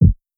MB Kick (35).wav